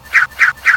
get_nunchucks.ogg